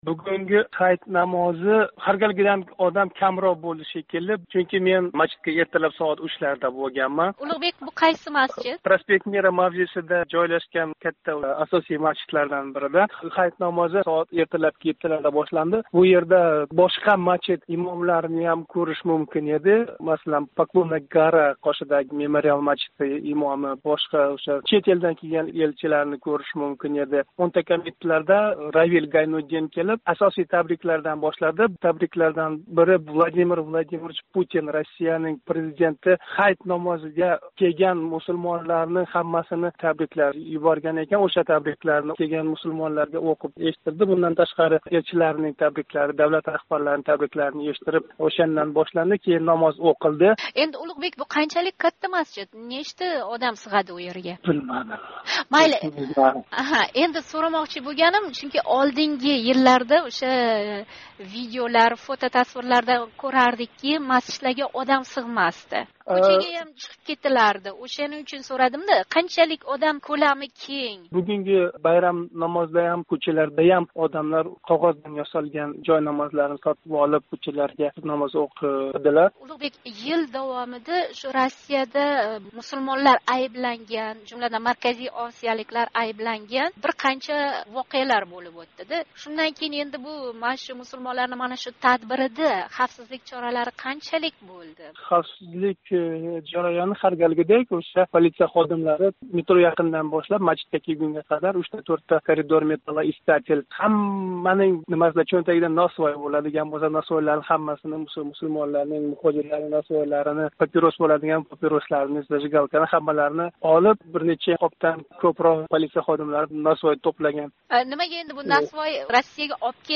Москвада Ҳайит намози ўқилди